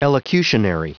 Prononciation du mot elocutionary en anglais (fichier audio)
elocutionary.wav